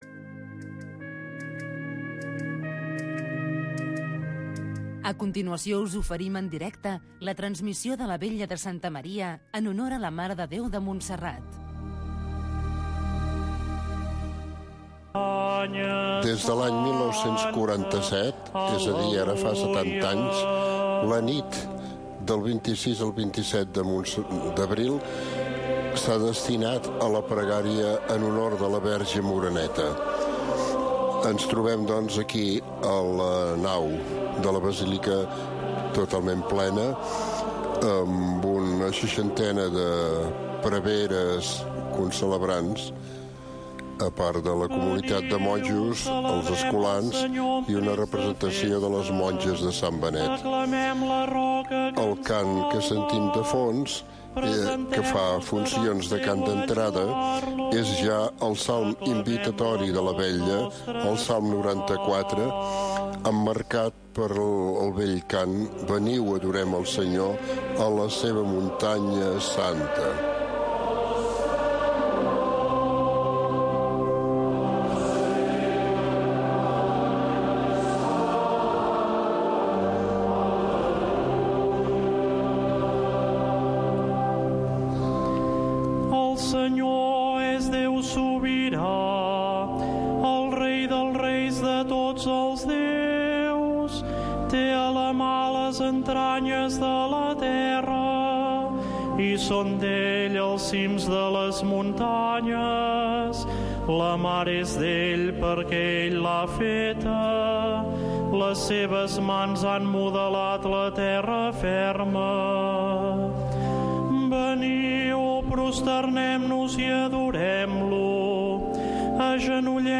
Retransmissió en directe de la missa conventual des de la basílica de Santa Maria de Montserrat.